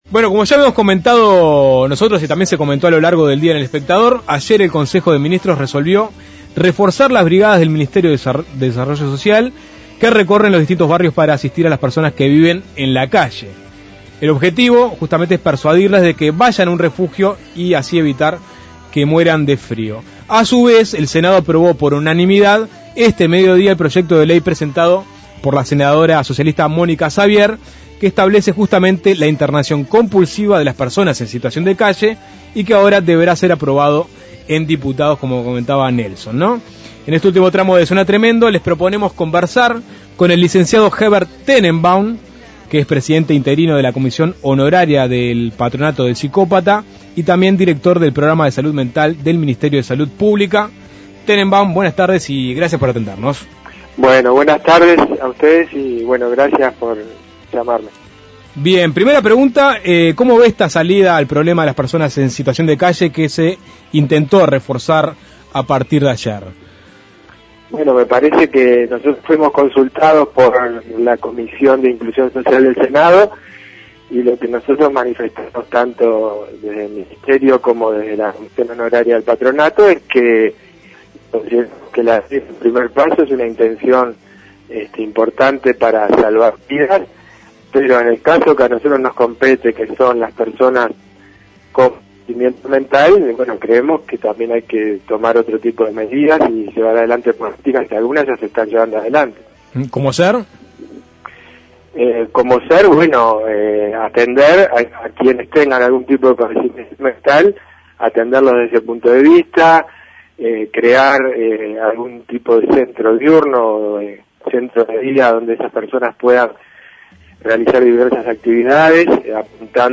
El Consejo de Ministros resolvió reforzar las brigadas del Mides que recorren los distintos barrios para asistir a las personas que viven en la vía pública. El Senado aprobó por unanimidad un proyecto que establece la internación compulsiva de las personas en situación de calle. Suena Tremendo conversó con el licenciado Hebert Tenenbaum, presidente interino de la Comisión Honoraria del Patronato del Psicópata y Director del Programa de Salud Mental del Ministerio de Salud Pública.